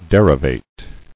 [der·i·vate]